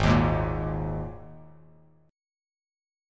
Absus2 chord